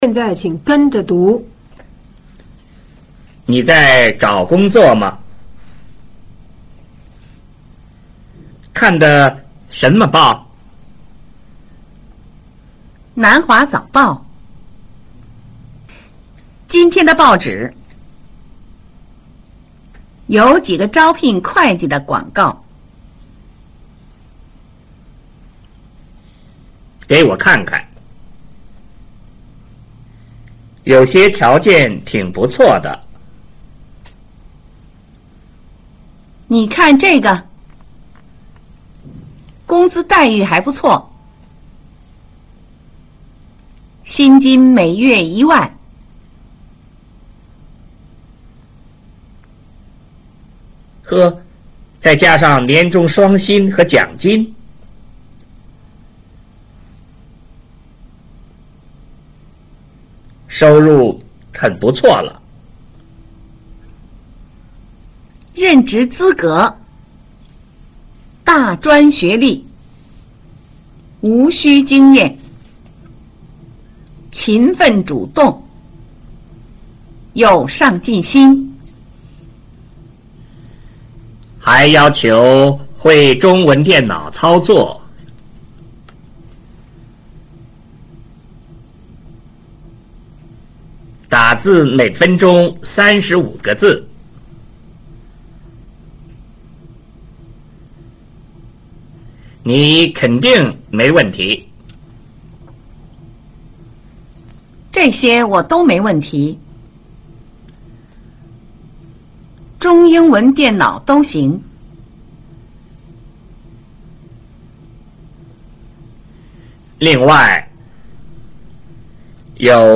跟讀